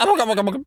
turkey_ostrich_gobble_05.wav